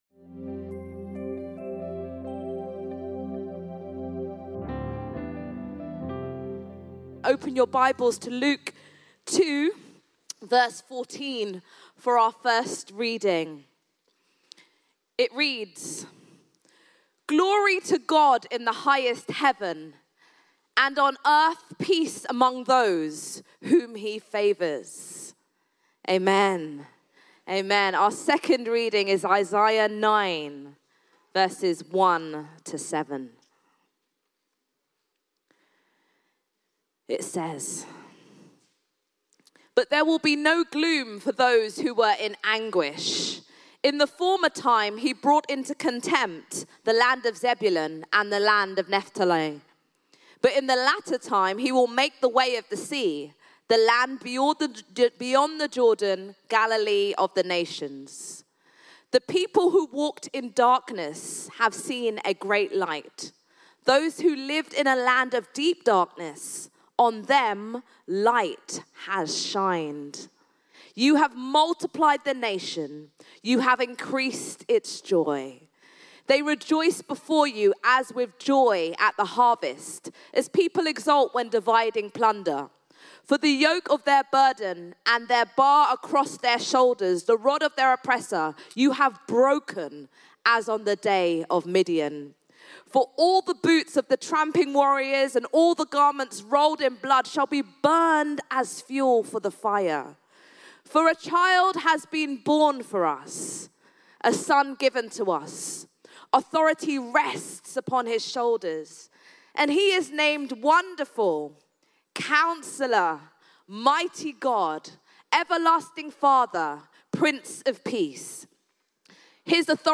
_Sermon Series